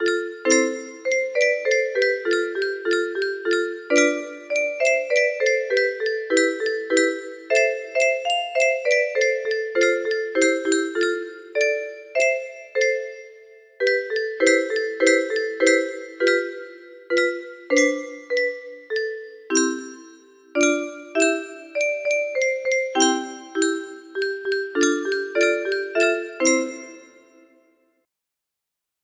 Clone of WE WISH YOU MERRY CHRISTMAS music box melody